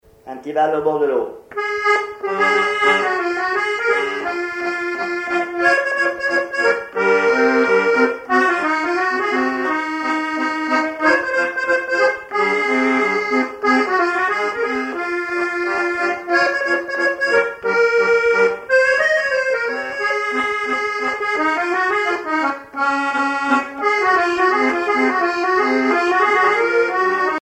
accordéon(s), accordéoniste
danse : java
Répertoire à l'accordéon chromatique
Pièce musicale inédite